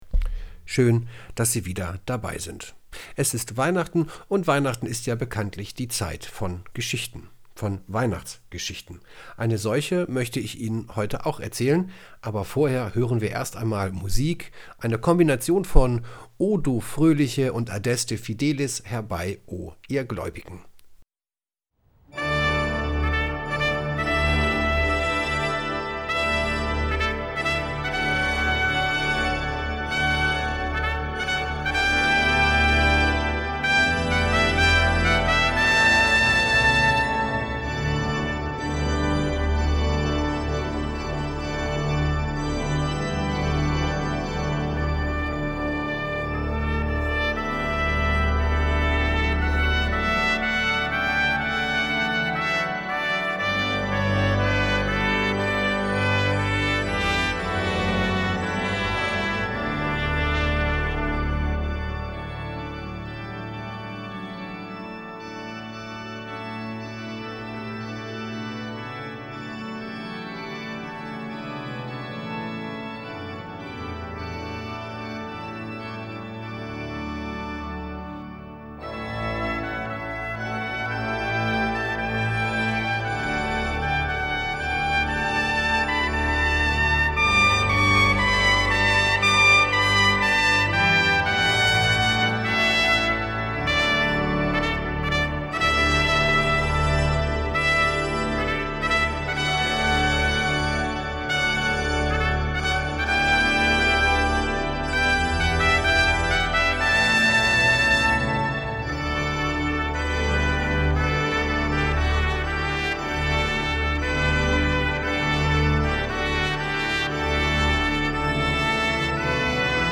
Weihnachtsandacht_2021_Schluessel_Harz.mp3